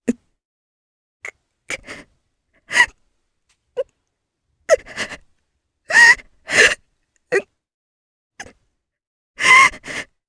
Hilda-Vox_Sad_jp_b.wav